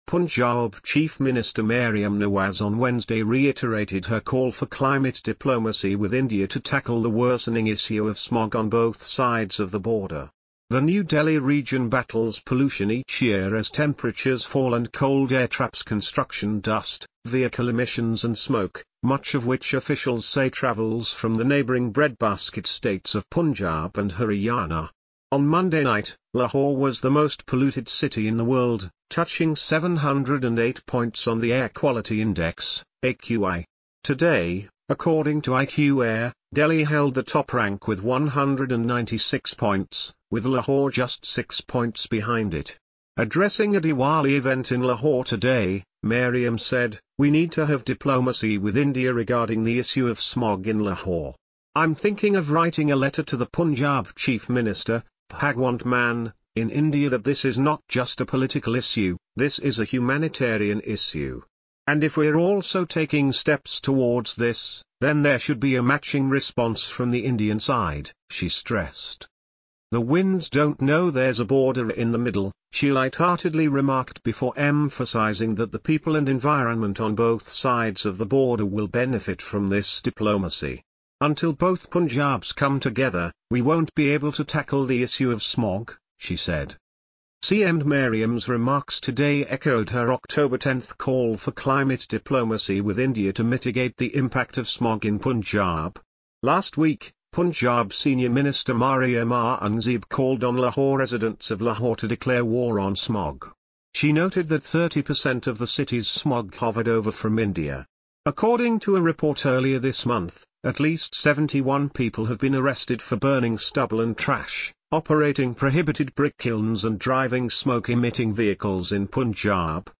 Punjab Chief Minister Maryam Nawaz addresses a Diwali event in Lahore on Oct 30, 2024.